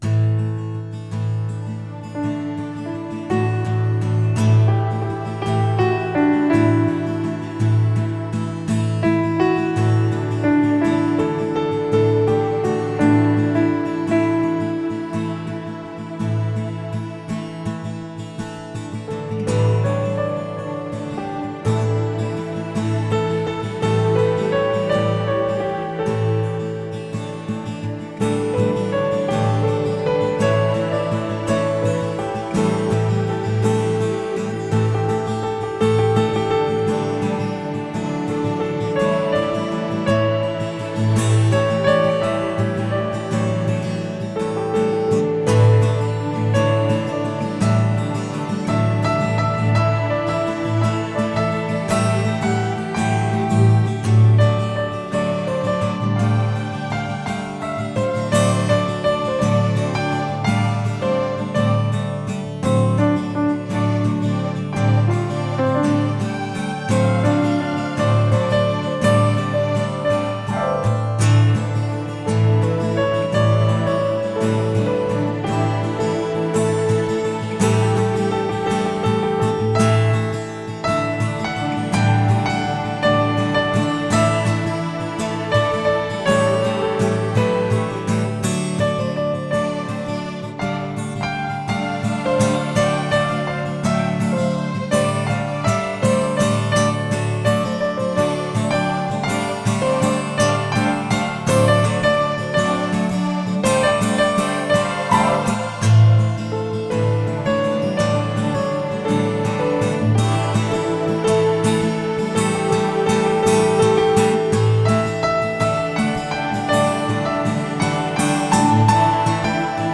Tempo: 56 bpm / Datum: 22.11.2016